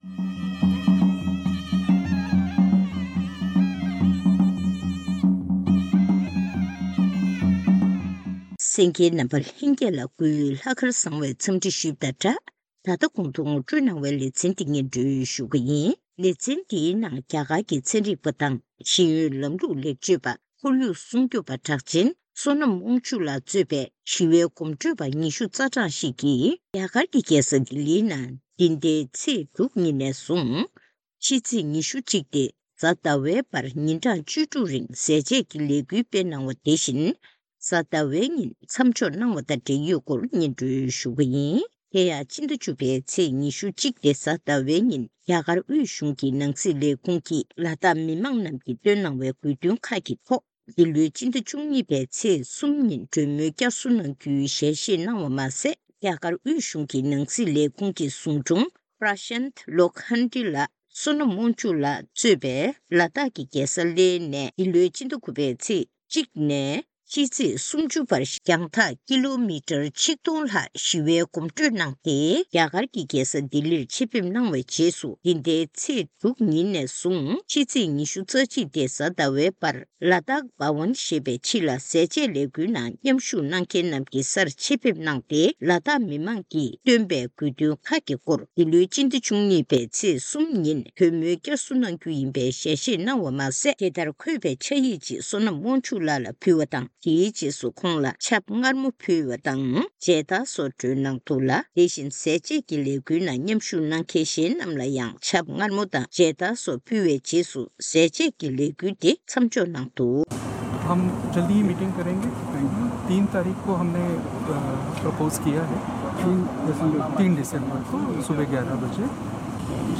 གནས་འདྲི་ཞུས་ནས་གནས་ཚུལ་ཕྱོགས་བསྒྲིགས་ཞུས་པ་ཞིག་གསན་རོགས་གནང།།